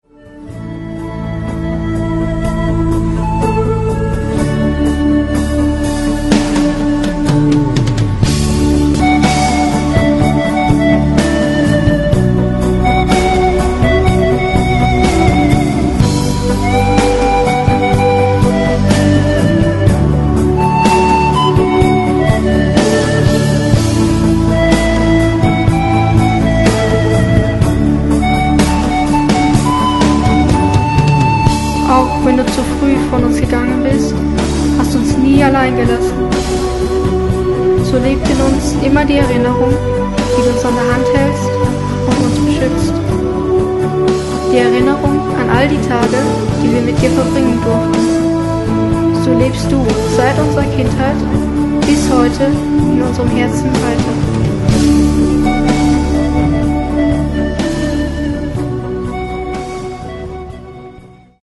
Panflöte